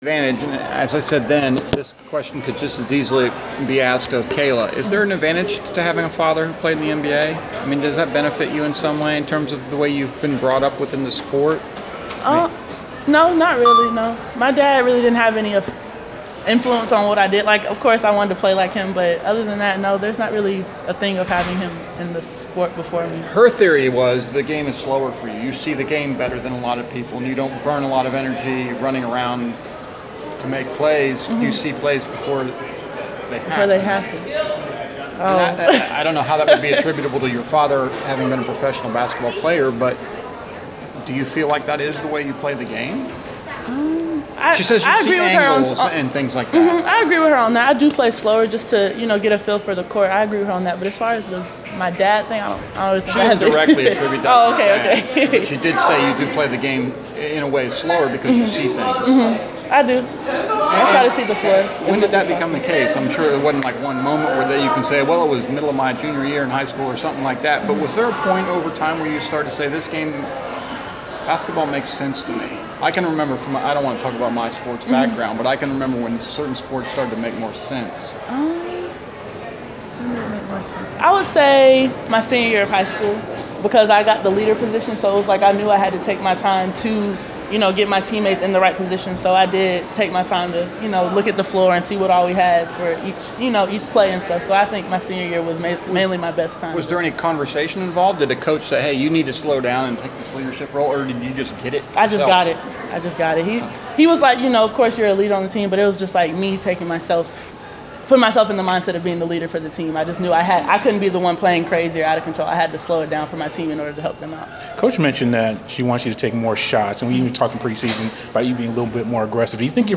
Inside the Inquirer: Postgame interview